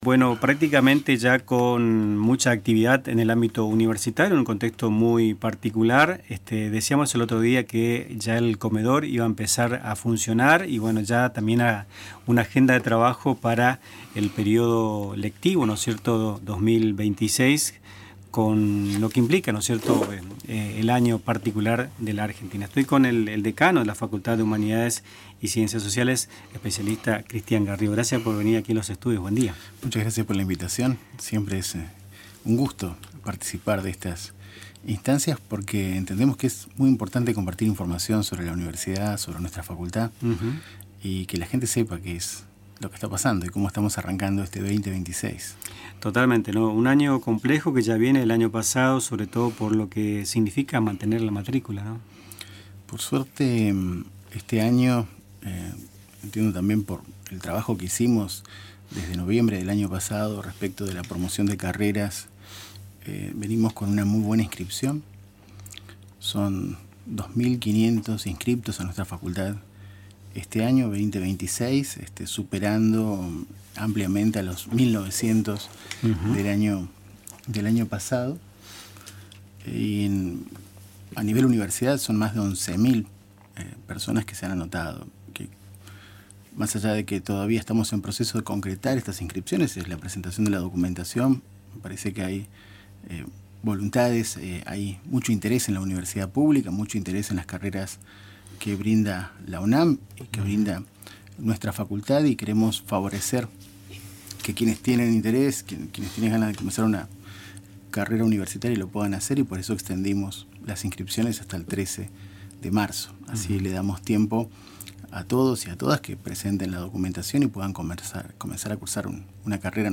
la entrevista completa